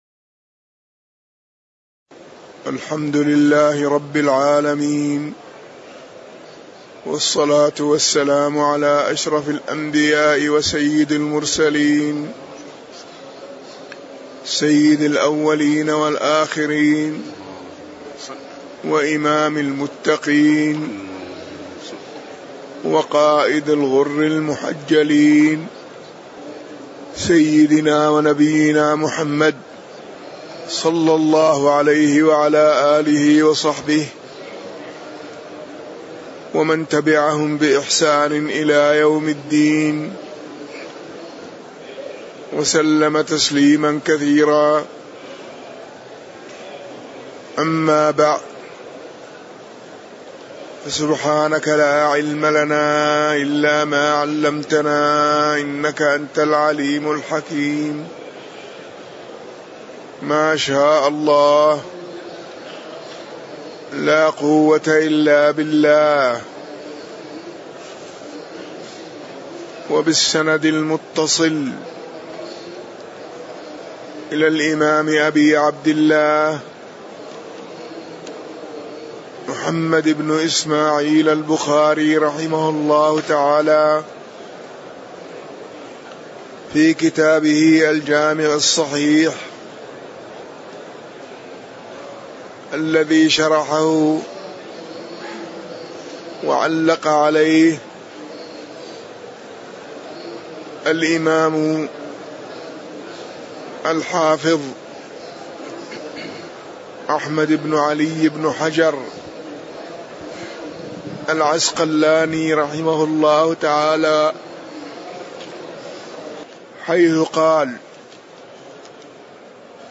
تاريخ النشر ١٥ ذو القعدة ١٤٣٨ هـ المكان: المسجد النبوي الشيخ